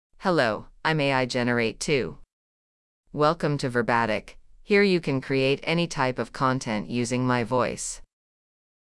FemaleEnglish (United States)
AIGenerate2Female English AI voice
AIGenerate2 is a female AI voice for English (United States).
Voice sample
Listen to AIGenerate2's female English voice.